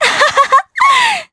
Laudia-Vox_Happy3_jp.wav